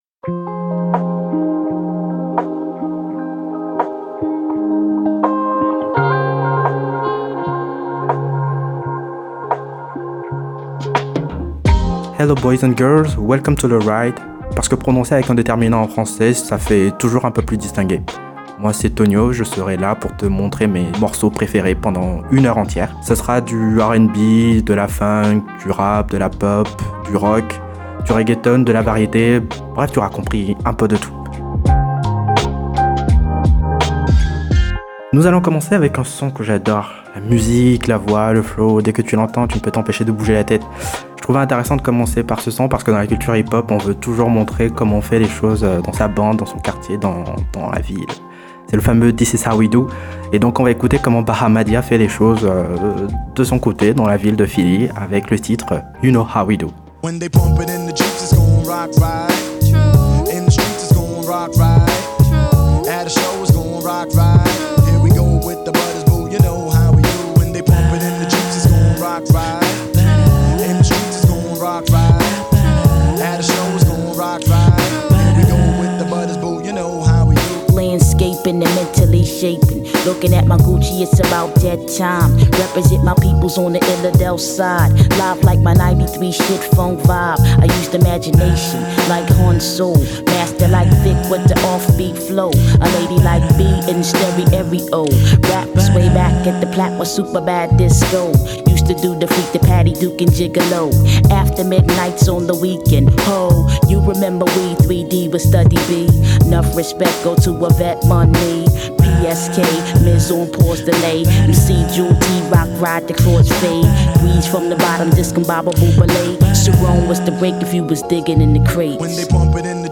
Le hip-hop US des années 80-90